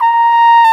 Index of /90_sSampleCDs/Roland L-CDX-03 Disk 2/BRS_Cornet/BRS_Cornet 2